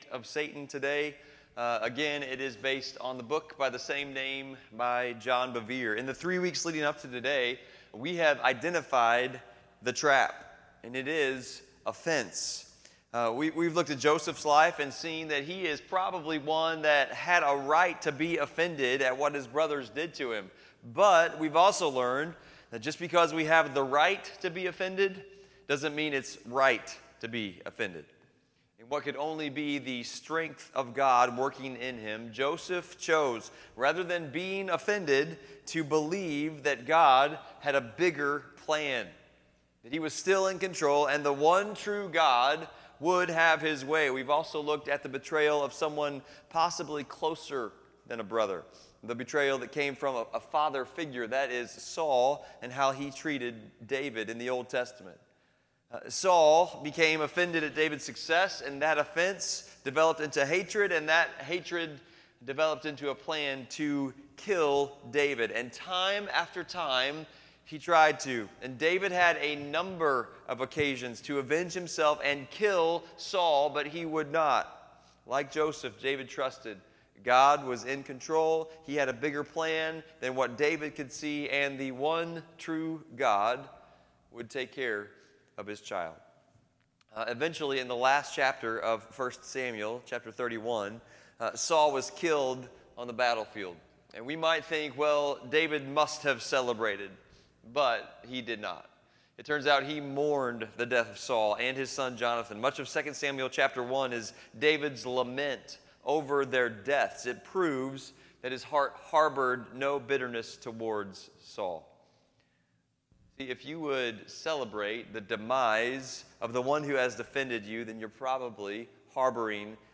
FBC Potosi - Sunday Service